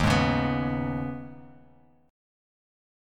D#7sus4#5 chord